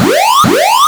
ALARM_Arcade_Fast_loop_stereo.wav